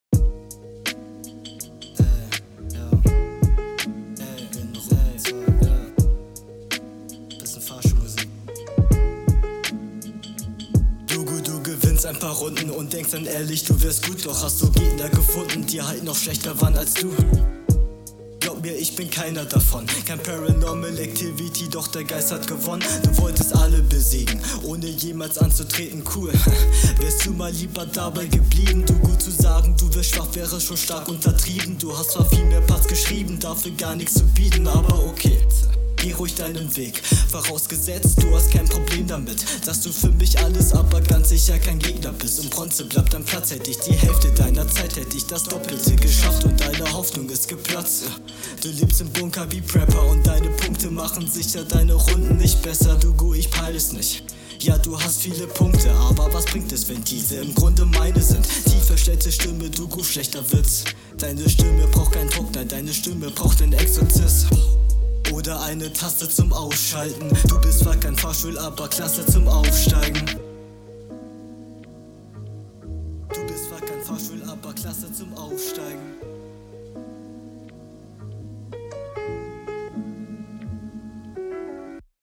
Schöner Bezug auf die Fahrstuhlmusik, aber ich glaube die Beatwahl wirkt sich nachteilig auf deinen …